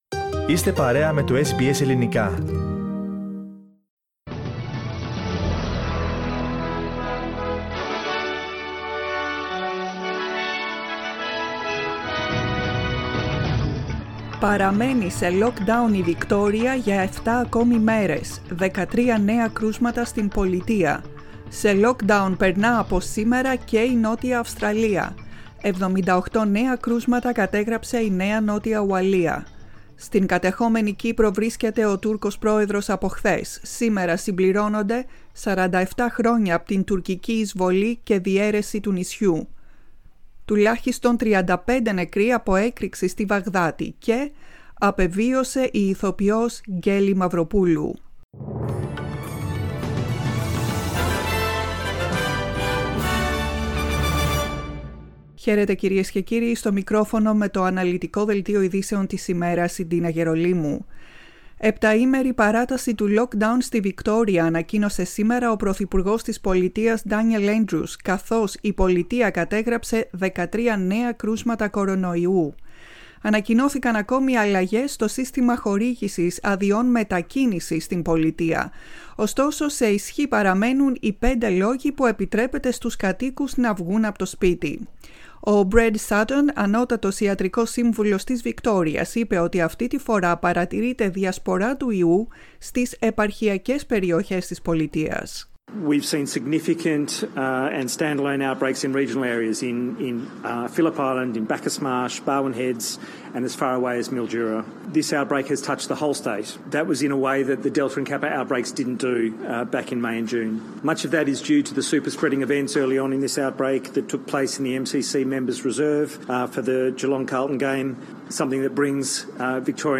Listen to the main bulletin of the day from the Greek Language Program.